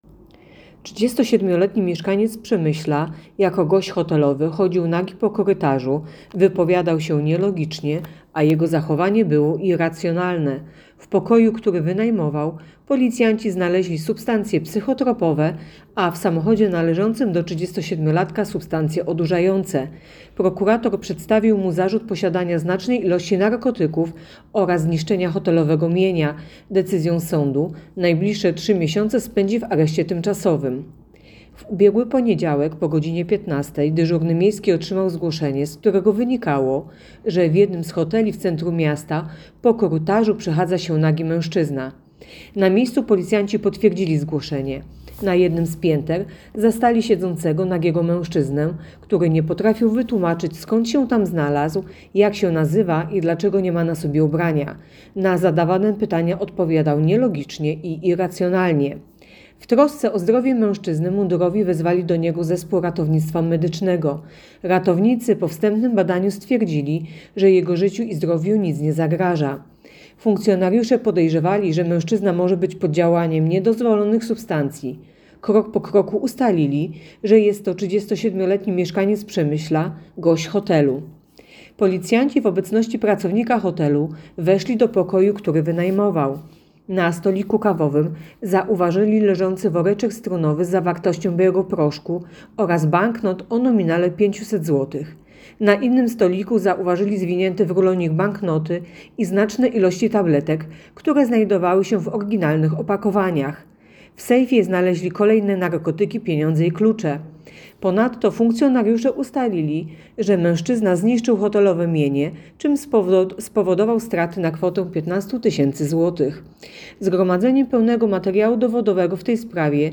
Opis nagrania: Nagranie informacji pt. Znaczne ilości narkotyków w hotelowym pokoju i samochodzie 37–latka.